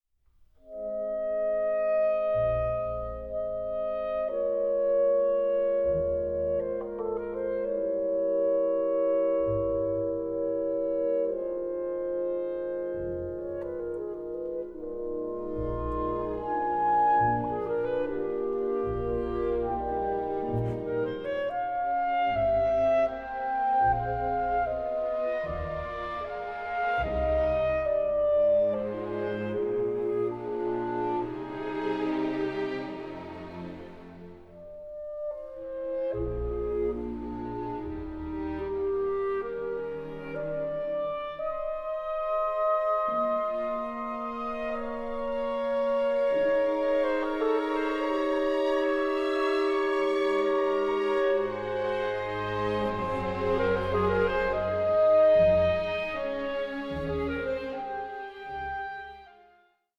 Andante un poco adagio 4:36